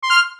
SFX_click.wav